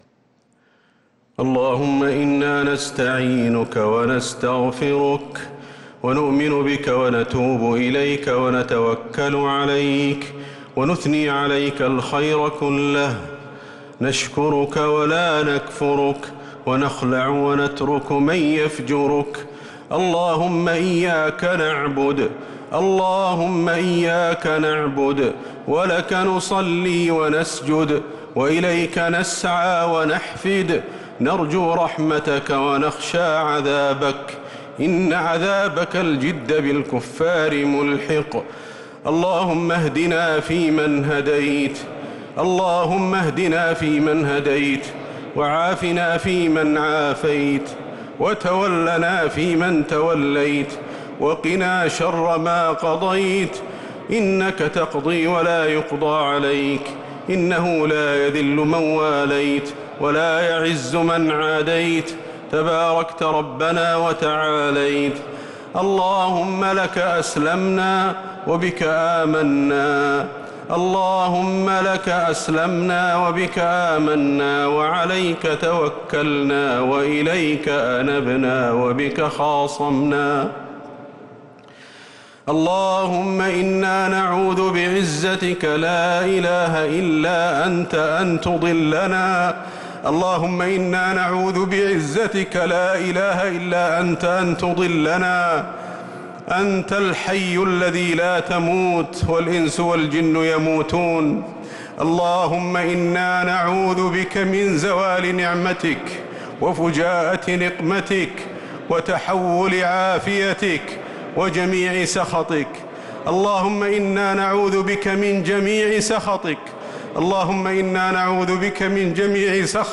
دعاء القنوت ليلة 24 رمضان 1446هـ | Dua 24th night Ramadan 1446H > تراويح الحرم النبوي عام 1446 🕌 > التراويح - تلاوات الحرمين